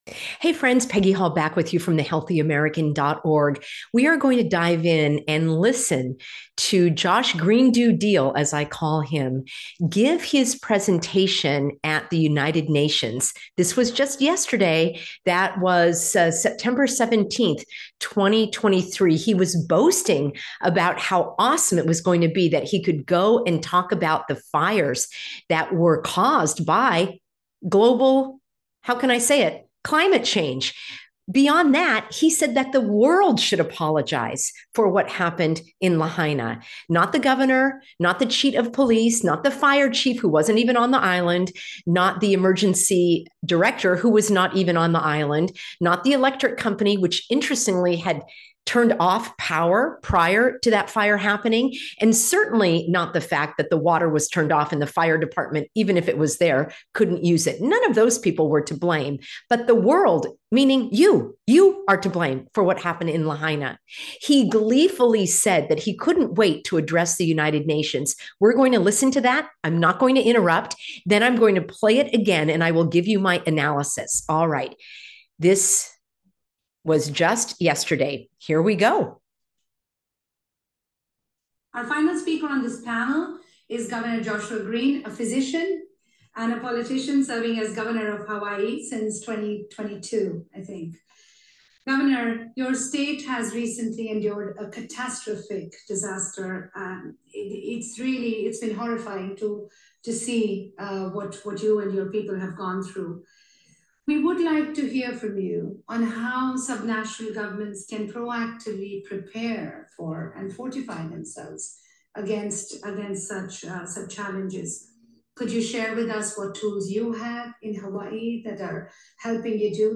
Hawaii Gov. Josh Green's UN Speech (last weekend!!)